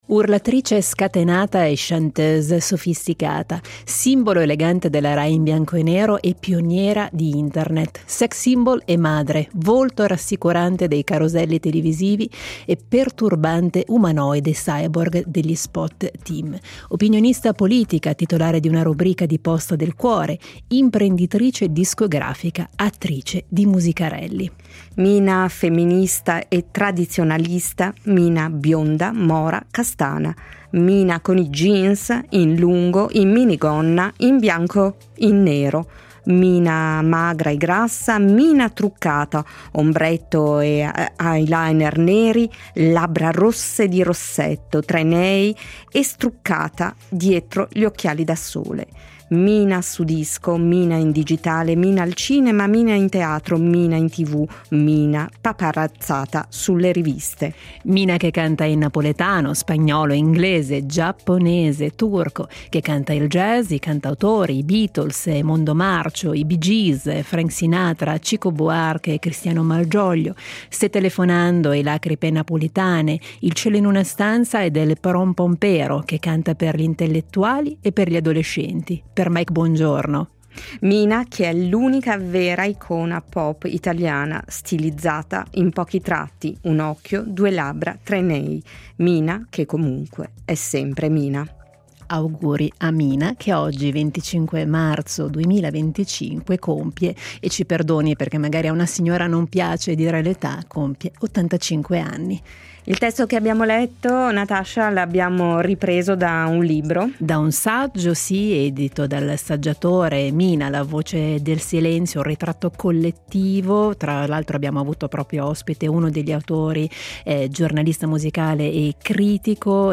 Gli auguri all’icona della musica pop italiana con una perla dagli archivi della RSI : un’intervista del 1962 in occasione della Tombola radiotelevisiva a Lugano